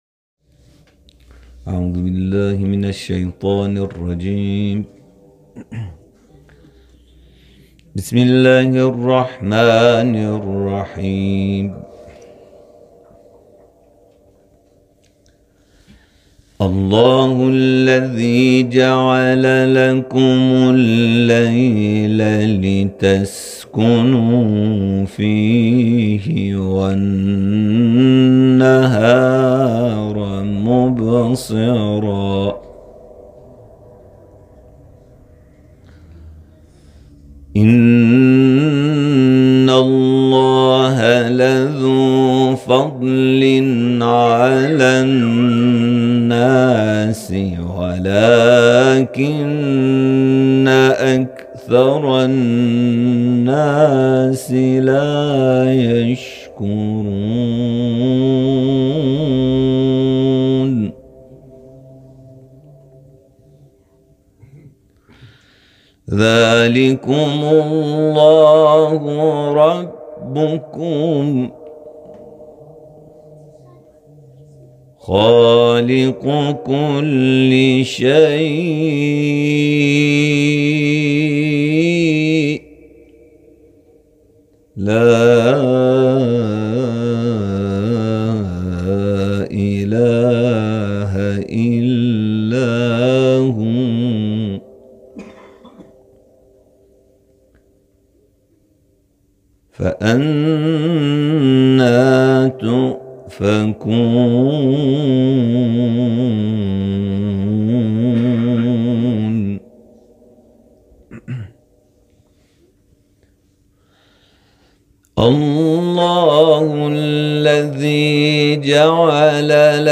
محفل قرآنی در آیندهوون هلند